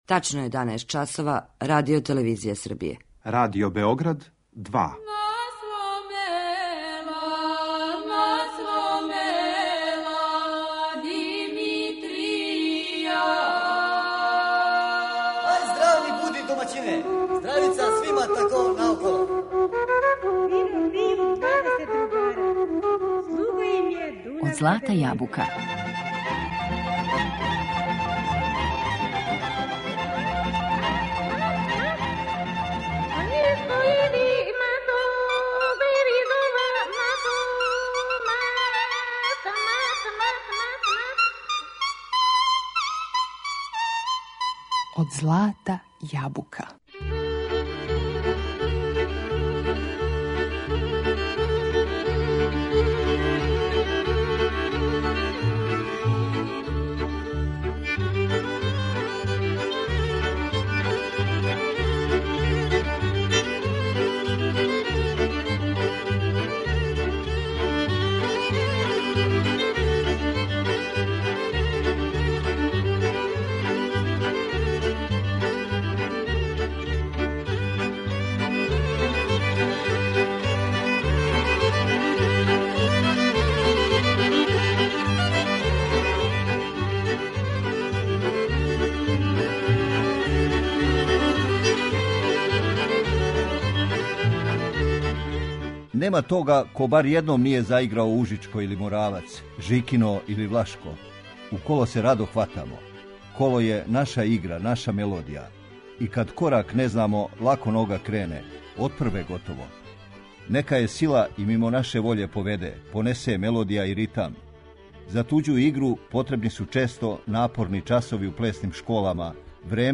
Слушамо нека од наших најлепших кола